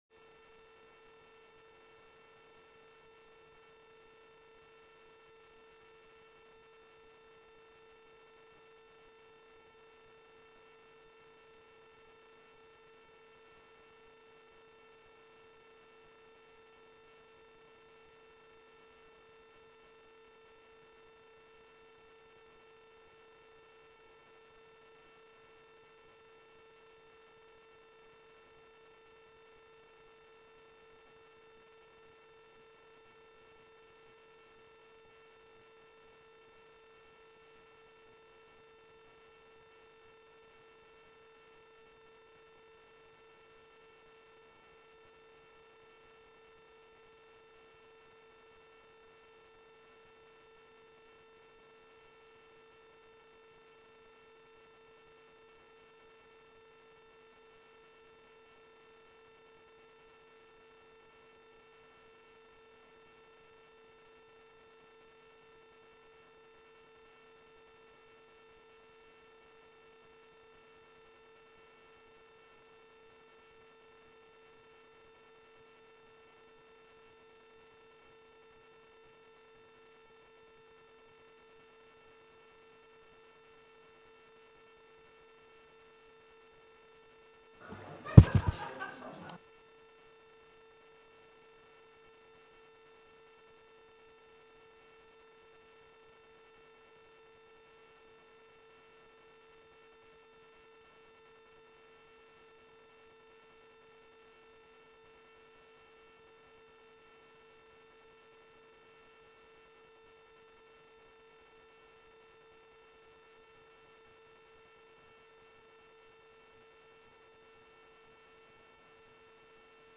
Raadscommissie Maatschappelijke Ontwikkeling 05 oktober 2015 19:30:00, Gemeente Den Helder
Locatie: Commissiekamer 1